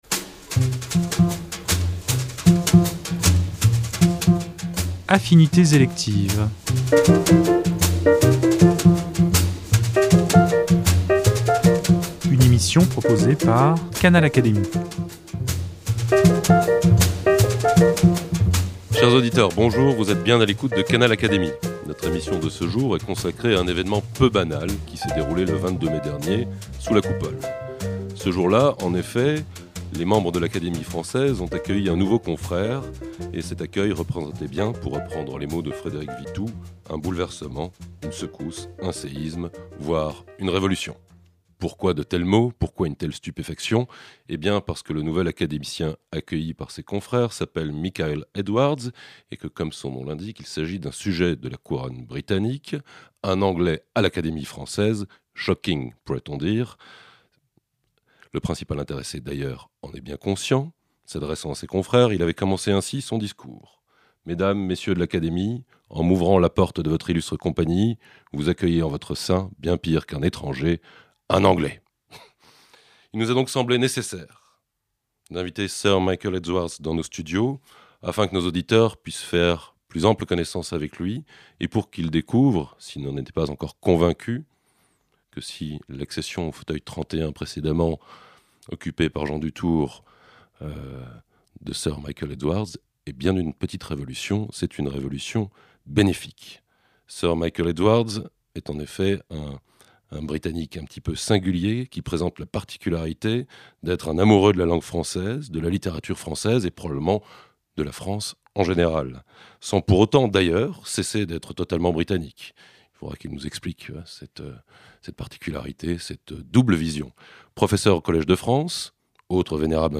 Dans cet entretien, le professeur et poète franco-anglais présente les idées qui lui sont chères et qu’il a développées tant dans son enseignement au Collège de France que dans ses vers. Il aborde ainsi les différences entre les manières françaises et britanniques d’être au monde et défend la nécessité de cultiver la vertu d’émerveillement et le « bonheur d’être ici ».